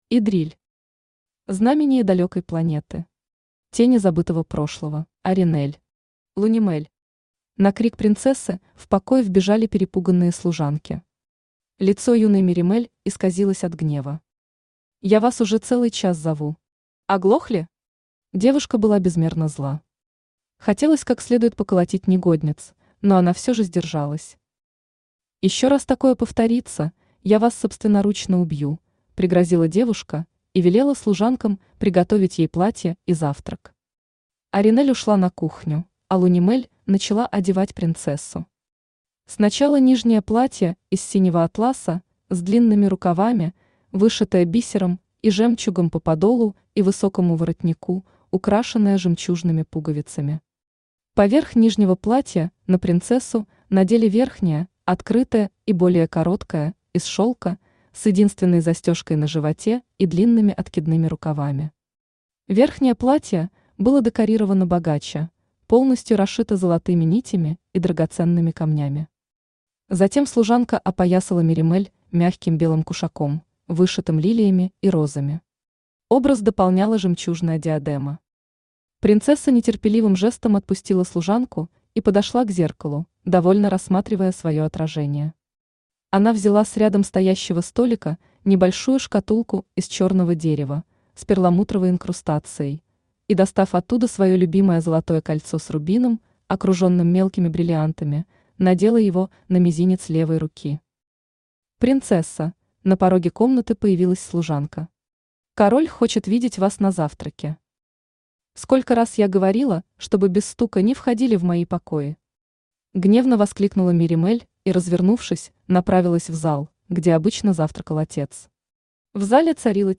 Тени забытого прошлого Автор Идриль Читает аудиокнигу Авточтец ЛитРес.